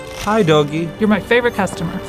reload2.wav